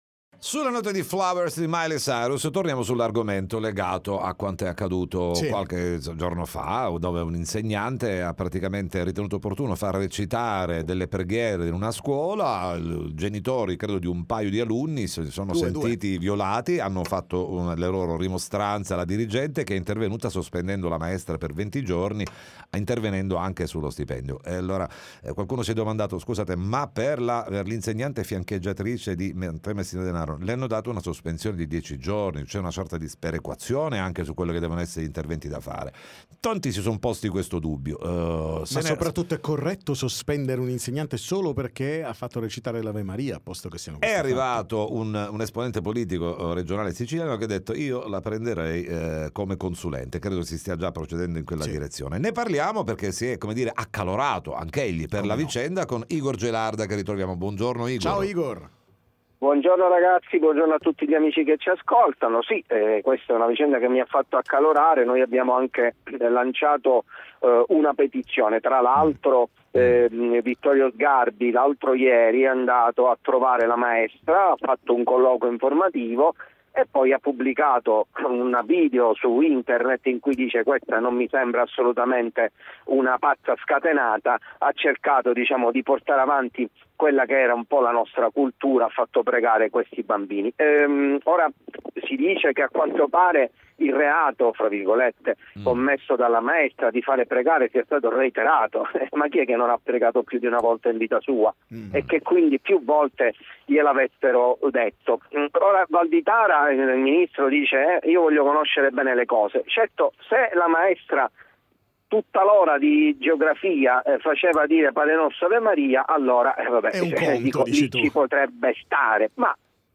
TM Intervista Igor Gelarda